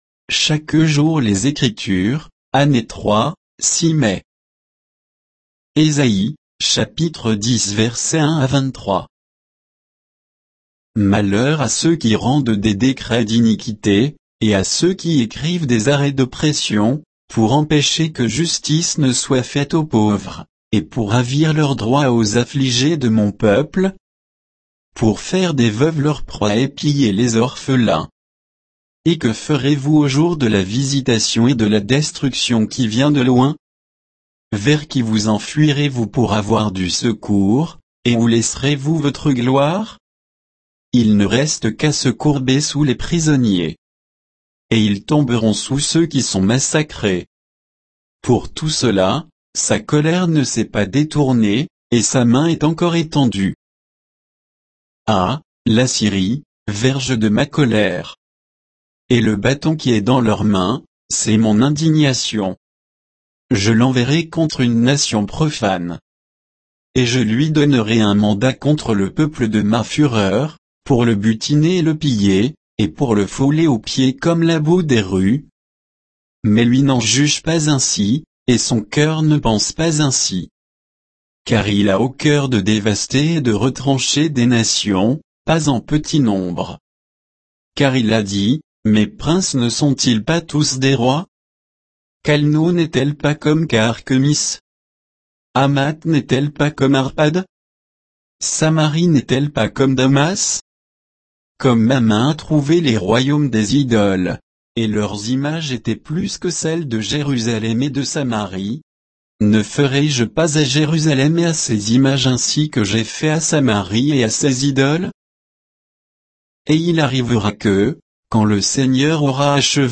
Méditation quoditienne de Chaque jour les Écritures sur Ésaïe 10